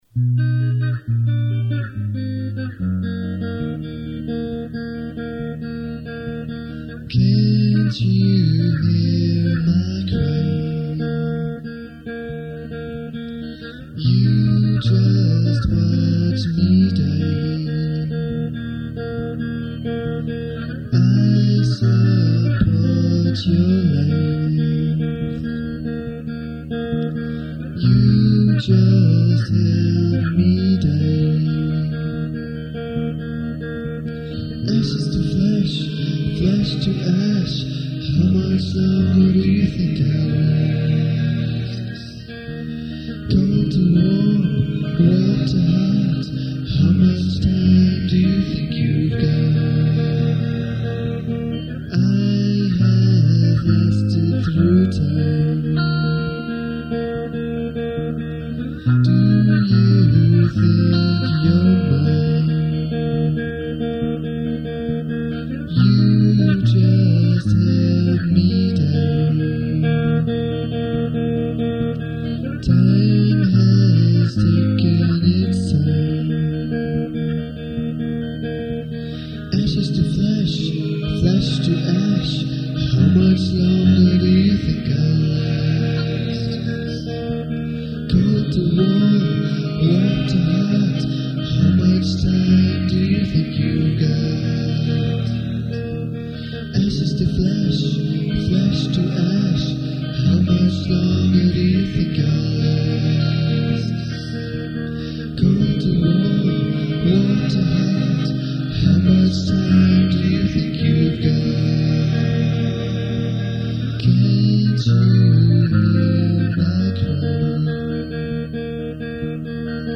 I have chosen a select few here that were recorded in the living room studio in "The Apartment" in the late '90s, around '98 or so.
These songs were written in extreme haste, recorded with more haste, with very little attention to detail, usually each instrument in one take only.
backing vox